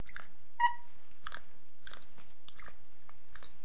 دانلود صدای حیوانات جنگلی 75 از ساعد نیوز با لینک مستقیم و کیفیت بالا
جلوه های صوتی